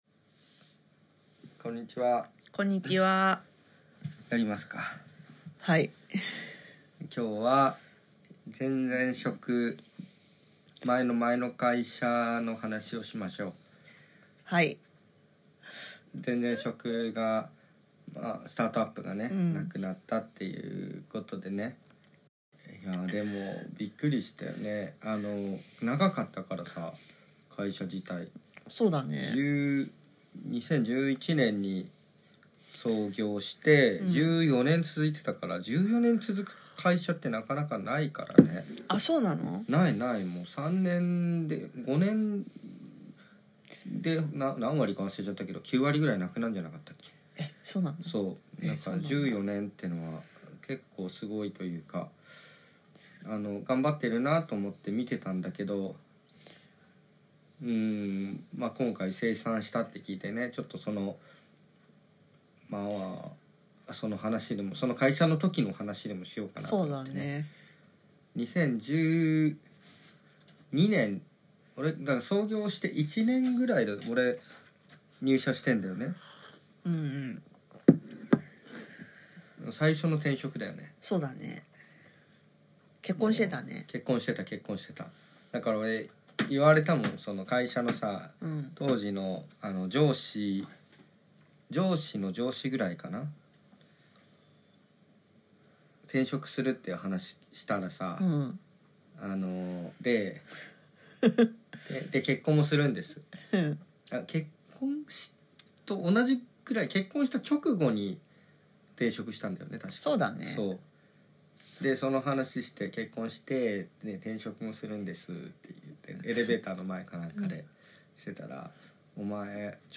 嫁氏と2人で、前々職のスタートアップの精算、クックパッドやカヤックへの憧れ、年俸の半減、何もできない焦燥感、起死回生のAndroidアプリリニューアル、ｼｬﾁｮｰとの1on1から始めたブログ/登壇、なくなった貯金、多国籍メンバーとの出会い、焦燥感からの転職、楽しそうだった夫などについて話しました。